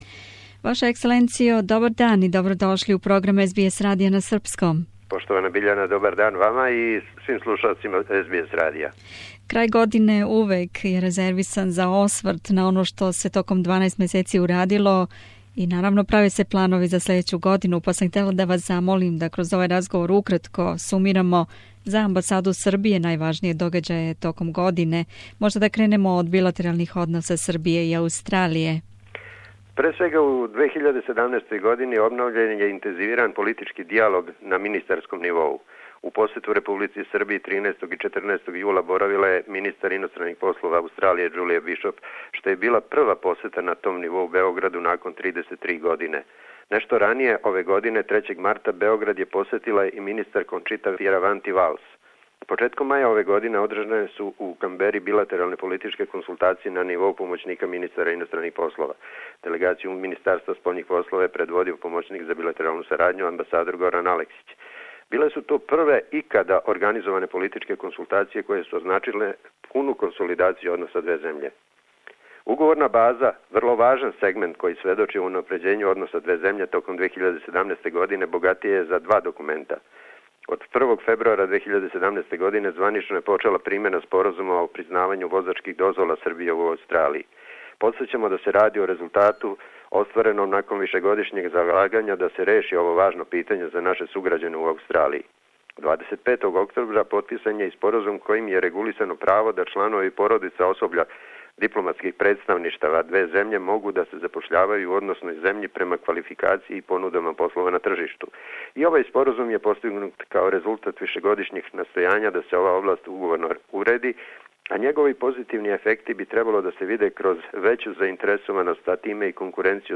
Кроз разговор са амбасадором Републике Србије у Камбери, Њ.Е. господином Мирољубом Петровићем, сумирамо за Амбасаду Републике Србије најважније догађаје током протекле године, почевши од билатералних односа две земље и важних билатералних споразума који су постигнути, преко привредне и економске сарадње и културних догађаја које је амбасада помогла током 2017, до постављања почасних конзула у Перту и Новом Зеланду. Такође више и о решавању трајног смештаја Амбасаде Републике Србије у Камбери.